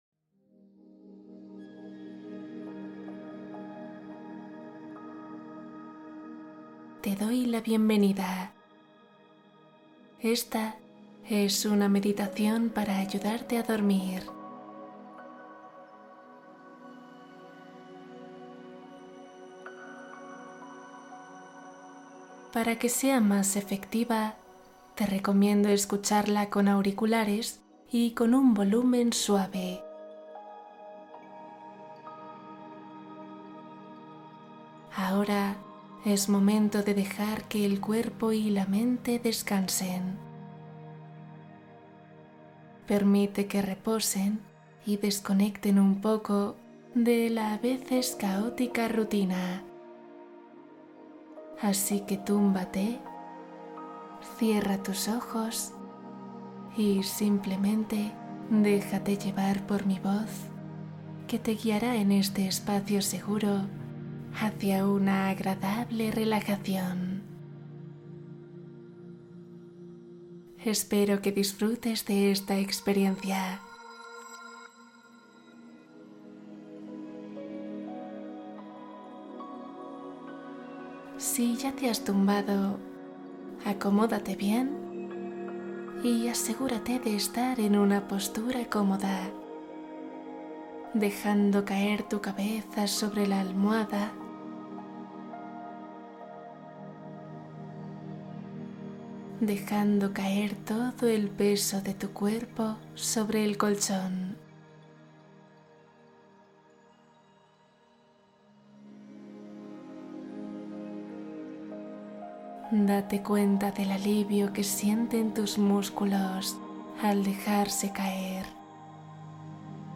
Ritual nocturno ❤ Meditación para limpieza energética y descanso profundo